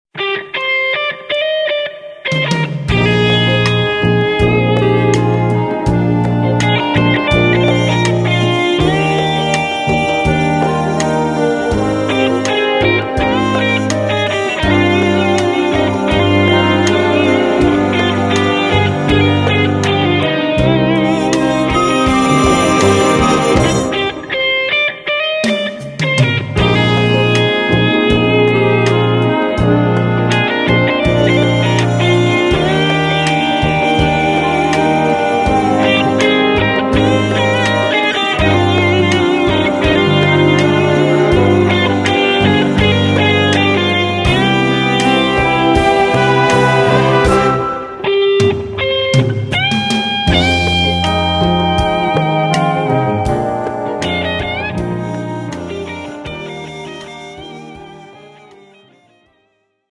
Gattung: Solo Gitarre und Blasorchester
A4 Besetzung: Blasorchester Zu hören auf